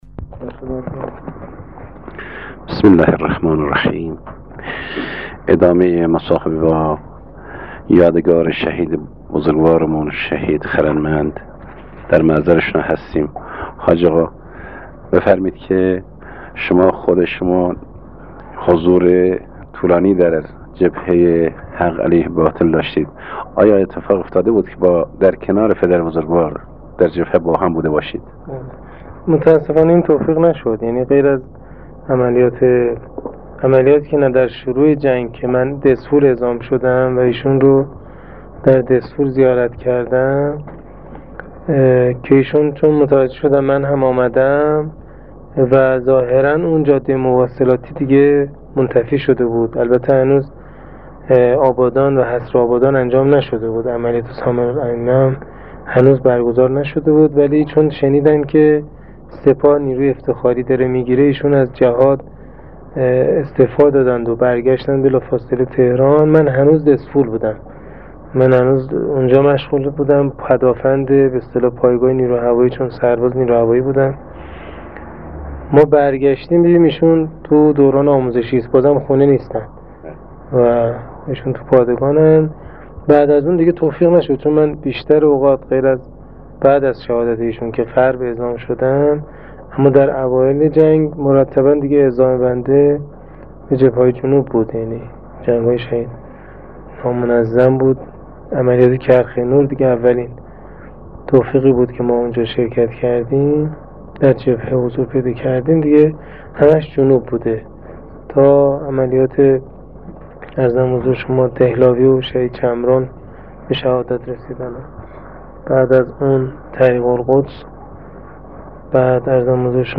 نوید شاهد خراسان جنوبی مصاحبه باخانواده و همرزم شهید